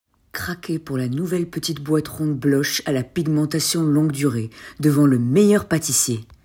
Voix off
- Contralto